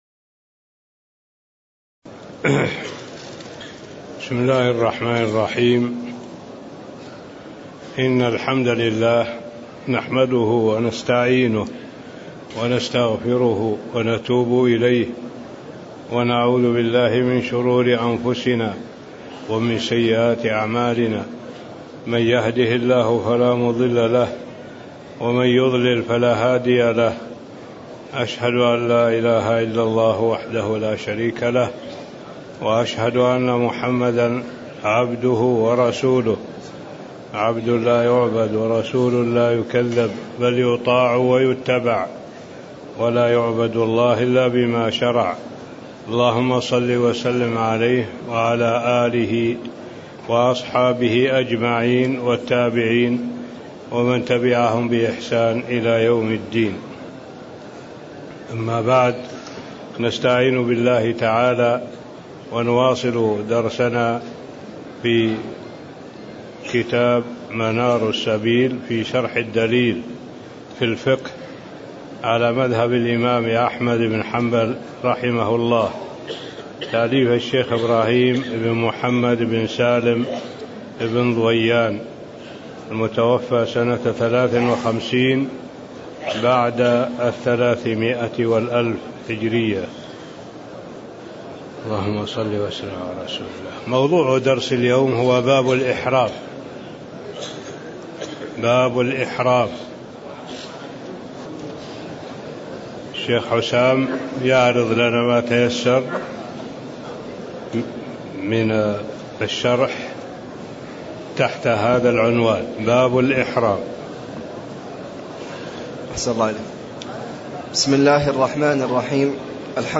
تاريخ النشر ١٤ ذو القعدة ١٤٣٦ هـ المكان: المسجد النبوي الشيخ